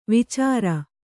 ♪ vicāra